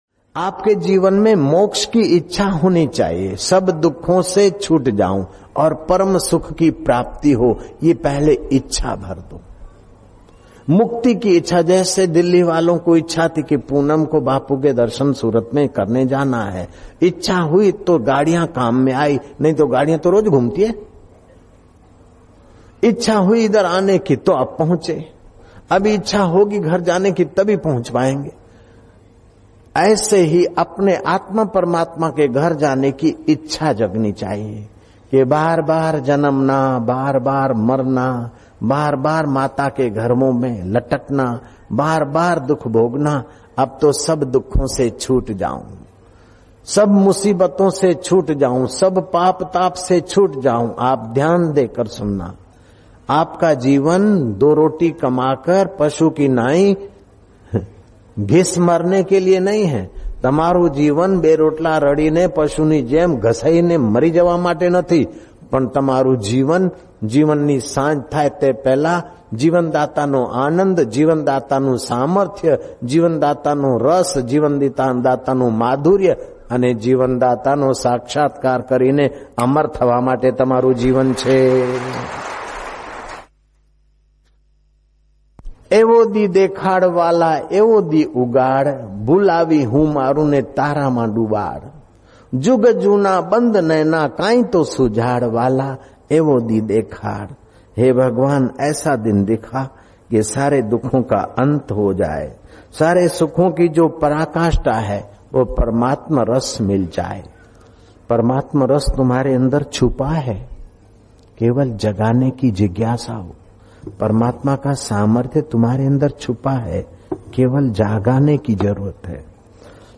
Nov 30,2024 Saturday : Evening : Sandhya Satsang - Evening